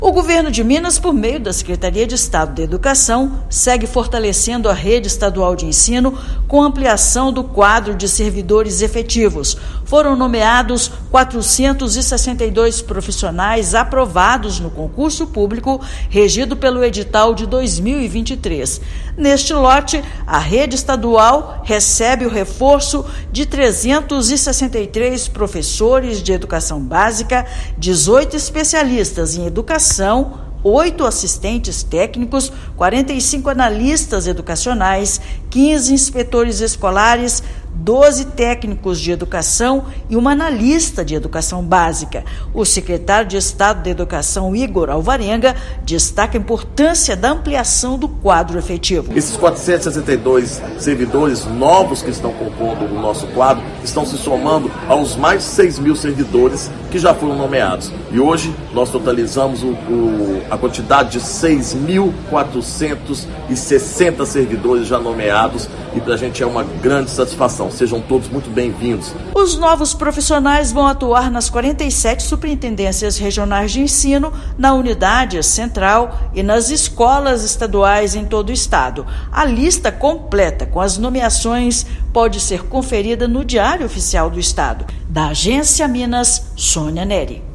Servidores irão atuar na unidade central da Secretaria de Estado de Educação (SEE/MG), em Belo Horizonte, nas 47 Superintendências Regionais de Ensino e nas escolas estaduais. Ouça matéria de rádio.